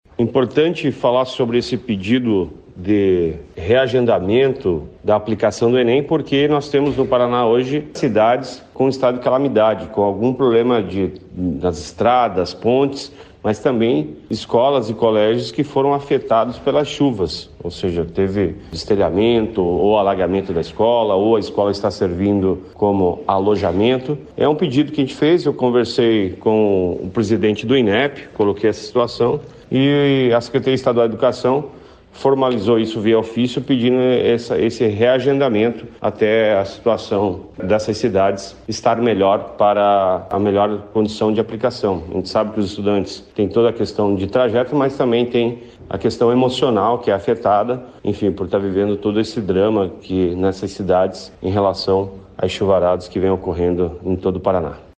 Sonora do secretário da Educação, Roni Miranda, sobre a solicitação do Paraná para que o Enem seja adiado em municípios afetados pelas chuvas